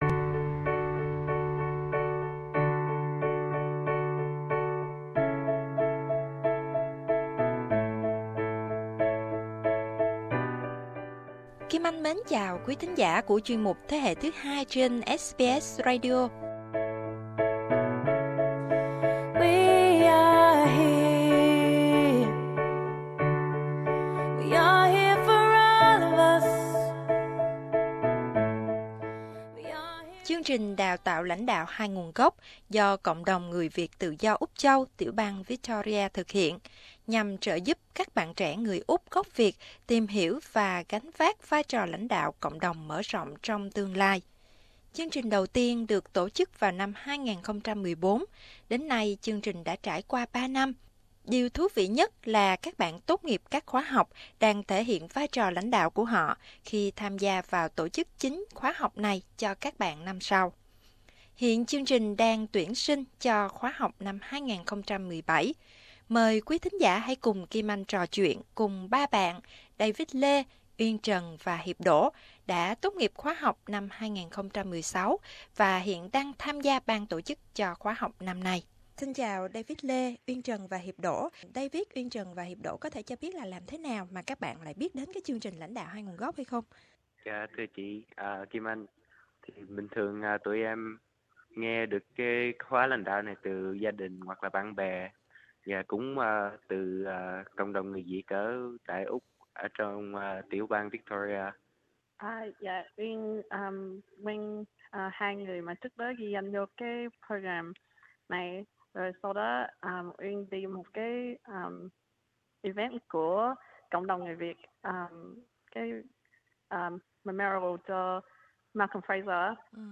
trò chuyện cùng ba khóa sinh Chương trình Đào tạo Lãnh đạo Hai Nguồn gốc